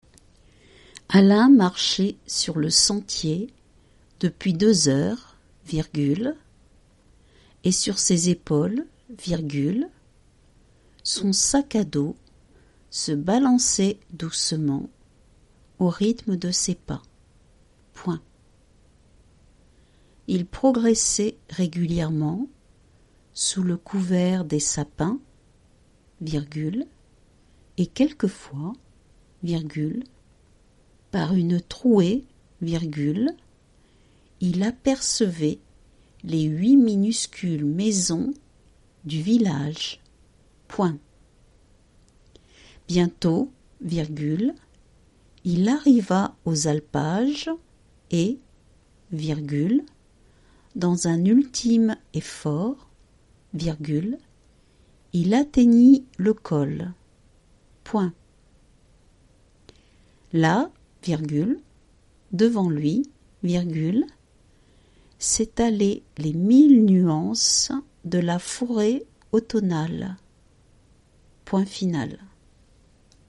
dictee-bilan.mp3